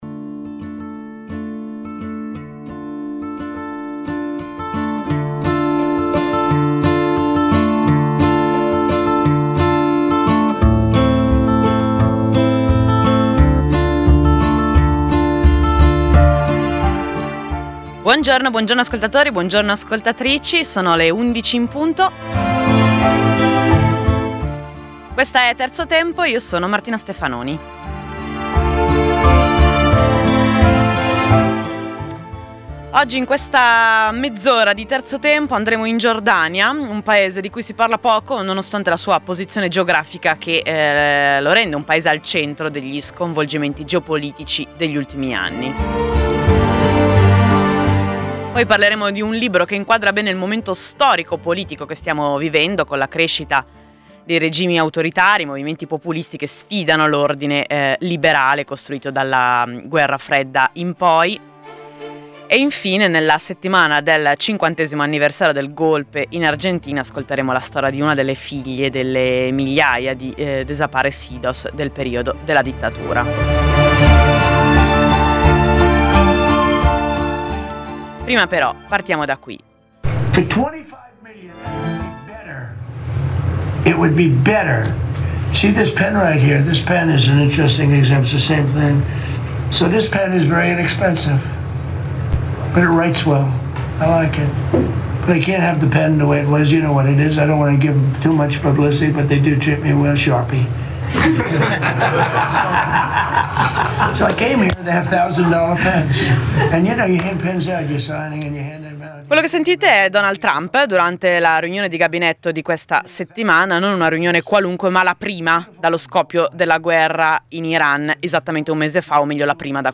Sarà una mezz’ora più rilassata rispetto all’appuntamento quotidiano, ricca di storie e racconti, ma anche di musica.